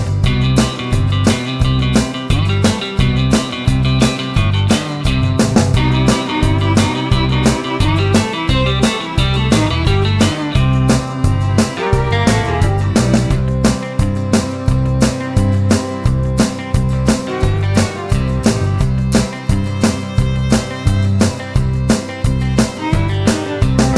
Country (Male)